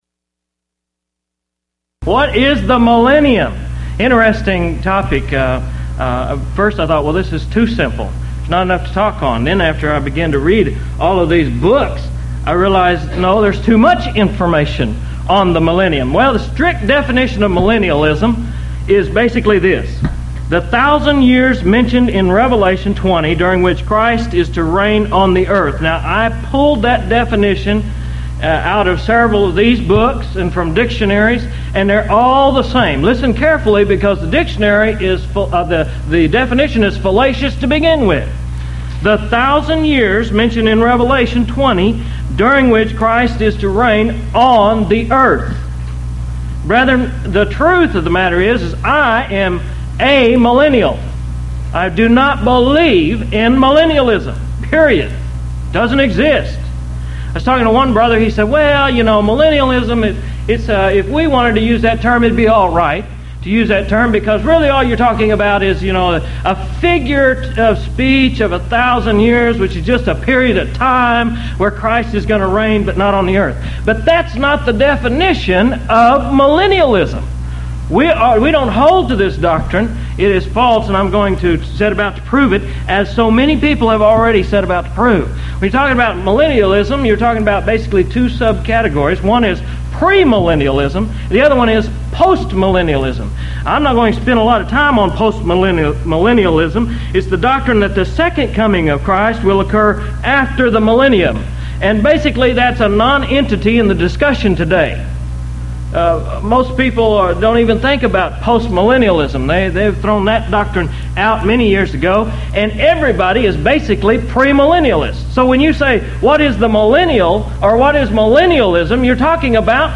Event: 1997 HCB Lectures